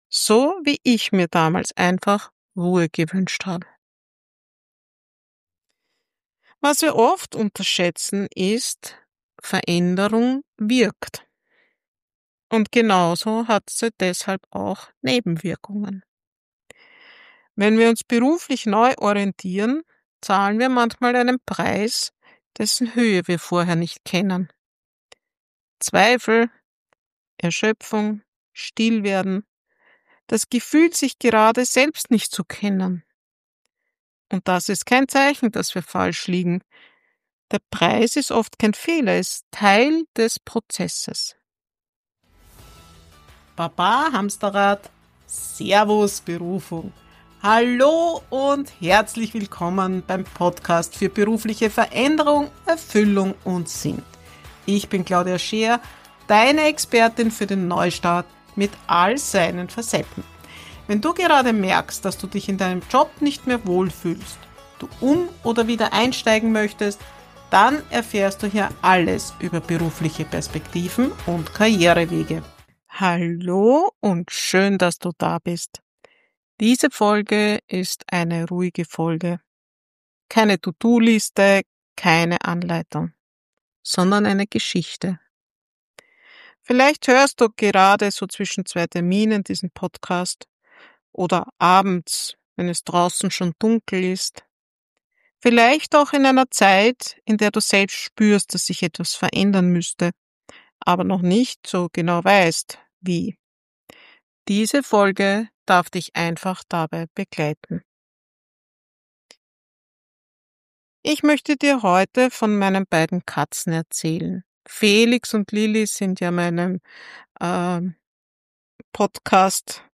Weihnachtsfolge